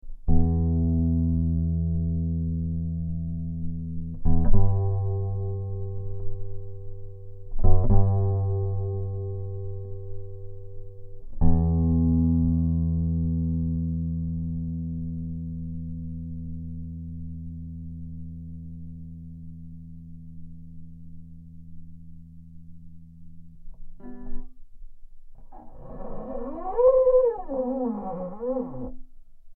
Geophone is an omnidirectional contact microphone.
Guitar